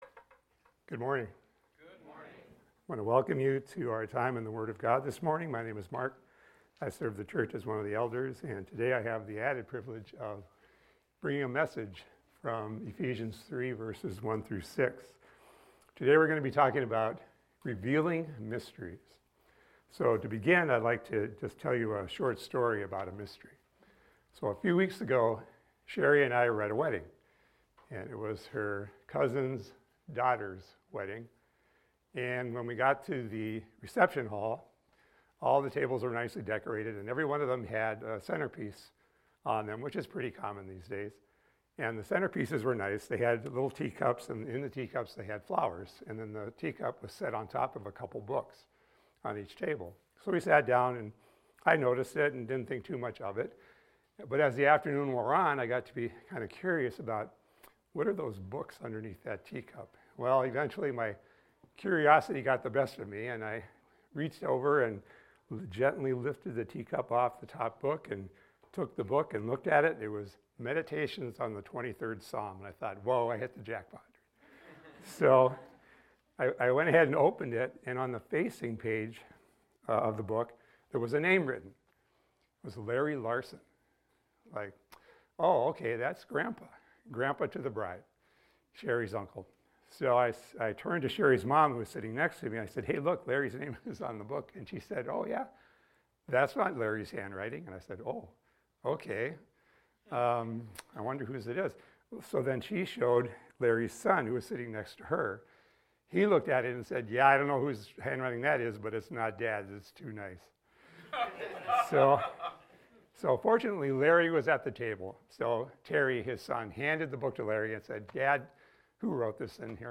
This is a recording of a sermon titled, "A Revealer of Mysteries."